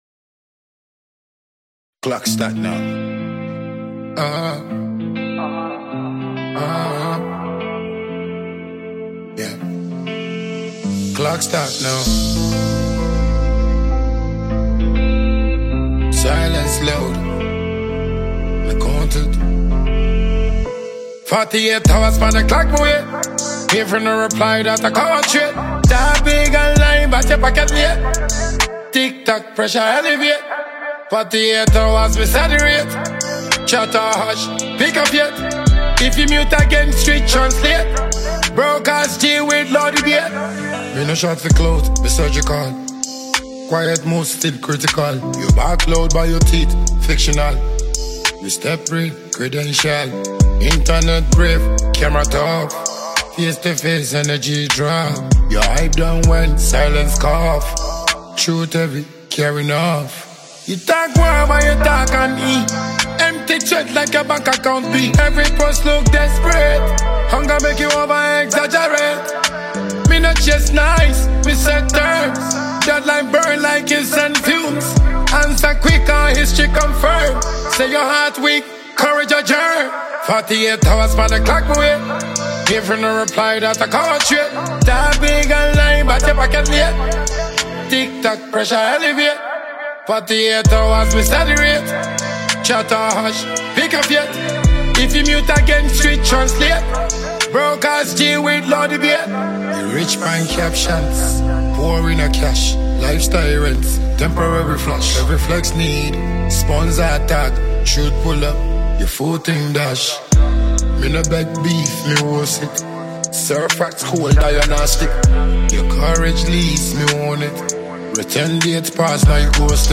A Sound of Authority and Energy
high-energy rhythm layered with street-ready lyrics
crisp percussion, heavy basslines, and infectious hooks